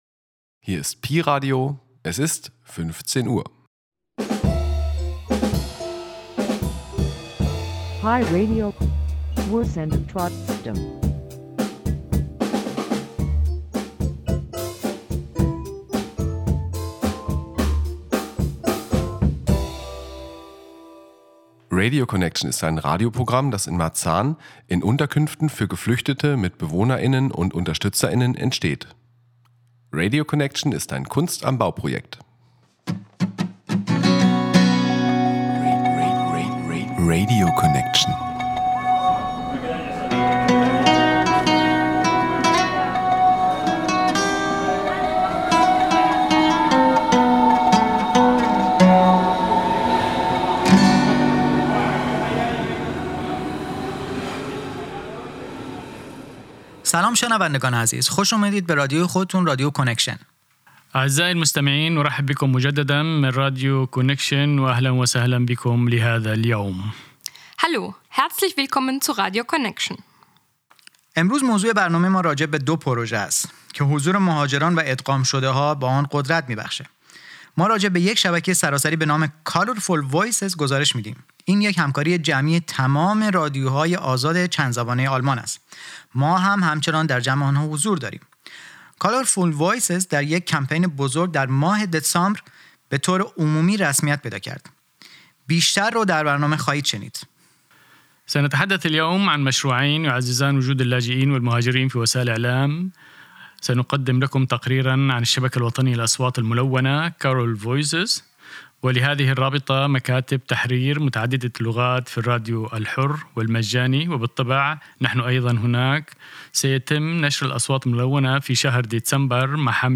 Interview mit Mediaresidents, Bericht über das Netzwerk Colourful Voices, mehrsprachige Redaktionen in freien Radios